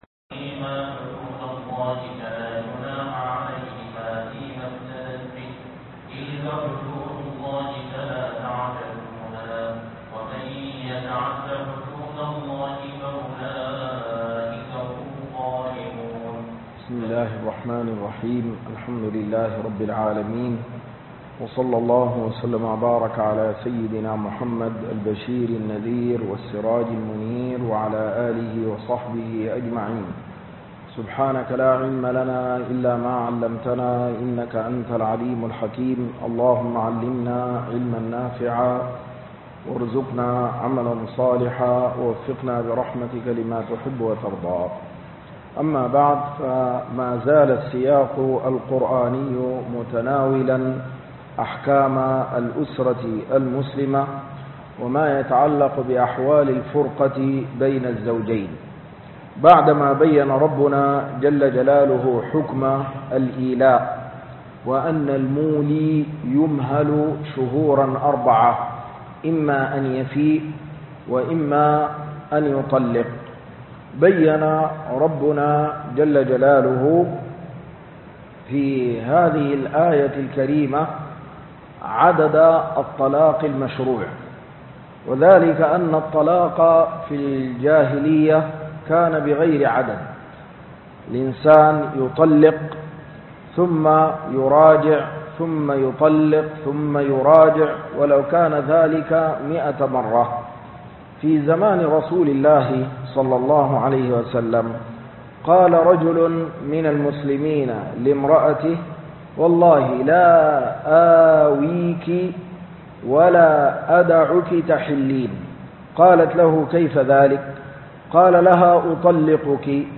الدرس 87 (تفسير سورة البقرة)